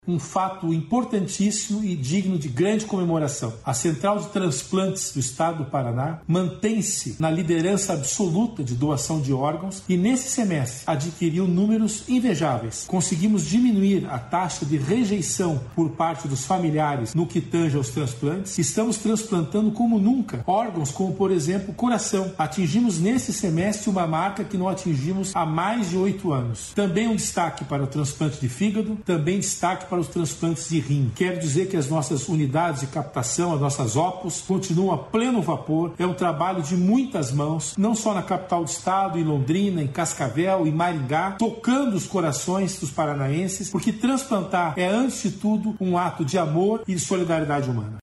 Sonora do secretário da Saúde, César Neves, sobre o aumento dos transplantes no 1º semestre